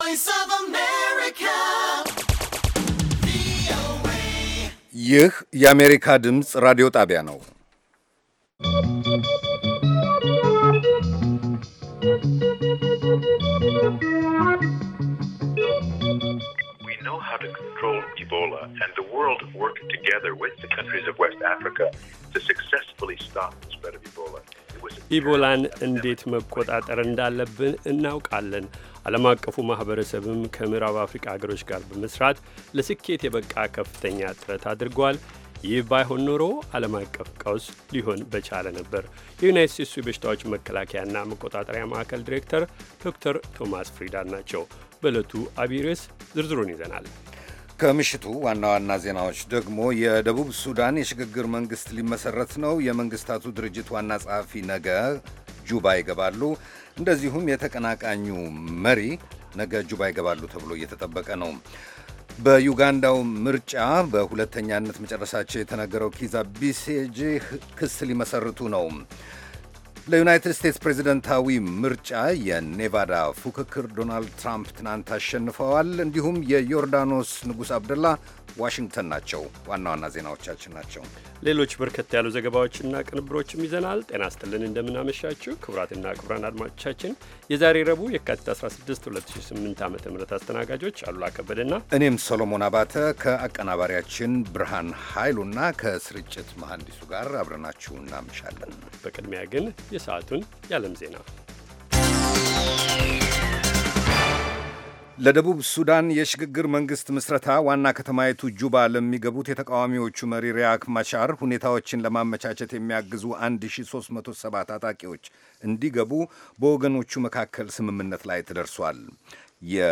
ከምሽቱ ሦስት ሰዓት የአማርኛ ዜና
ቪኦኤ በየዕለቱ ከምሽቱ 3 ሰዓት በኢትዮጵያ ኣቆጣጠር ጀምሮ በአማርኛ፣ በአጭር ሞገድ 22፣ 25 እና 31 ሜትር ባንድ የ60 ደቂቃ ሥርጭቱ ዜና፣ አበይት ዜናዎች ትንታኔና ሌሎችም ወቅታዊ መረጃዎችን የያዙ ፕሮግራሞች ያስተላልፋል። ረቡዕ፡- ዴሞክራሲ በተግባር፣ ሴቶችና ቤተሰብ፣ አሜሪካና ሕዝቧ፣ ኢትዮጵያዊያን ባሜሪካ